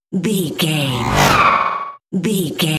Sci fi airy whoosh
Sound Effects
futuristic
tension
whoosh